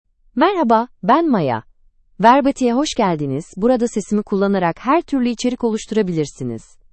MayaFemale Turkish AI voice
Maya is a female AI voice for Turkish (Turkey).
Voice sample
Listen to Maya's female Turkish voice.
Female
Maya delivers clear pronunciation with authentic Turkey Turkish intonation, making your content sound professionally produced.